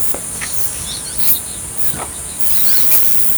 Pitanguá (Megarynchus pitangua)
Nombre en inglés: Boat-billed Flycatcher
Localidad o área protegida: Parque Nacional Mburucuyá
Condición: Silvestre
Certeza: Fotografiada, Vocalización Grabada